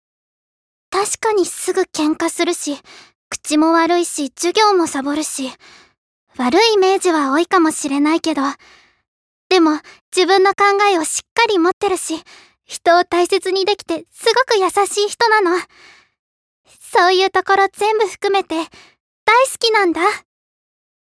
ボイスサンプル
優しい高校生